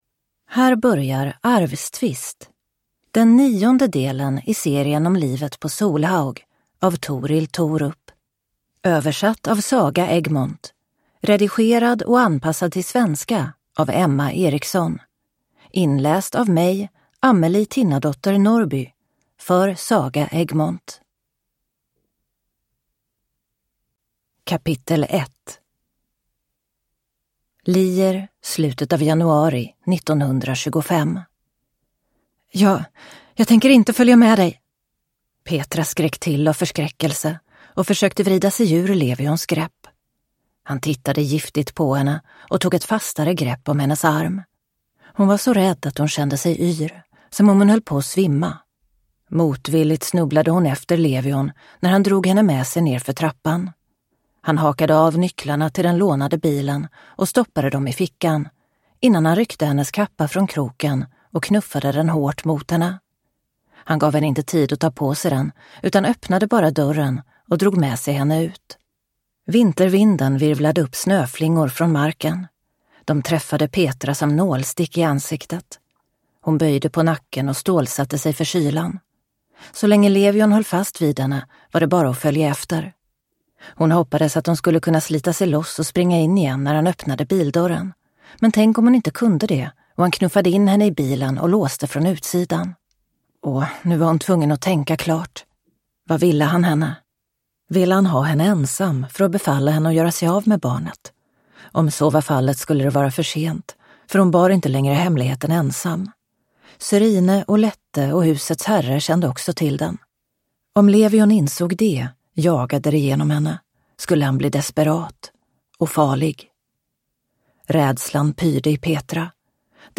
Arvstvist – Ljudbok